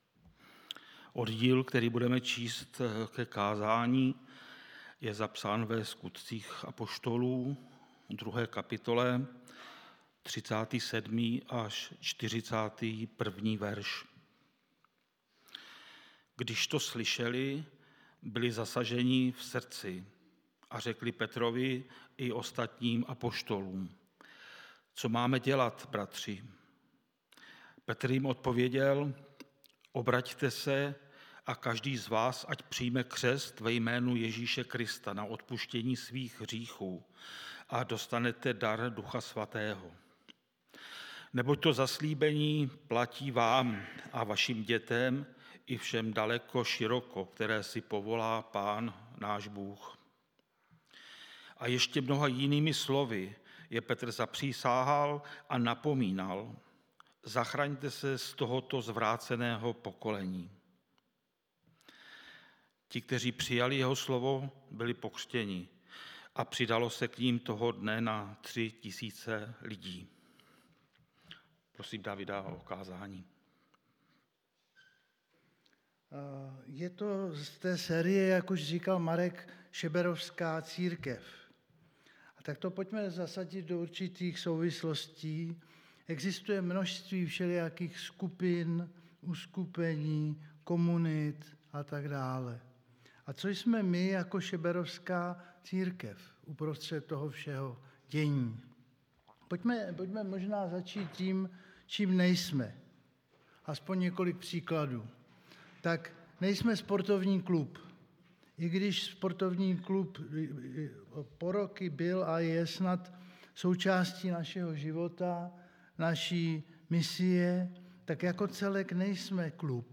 Nedělní bohoslužby přehrát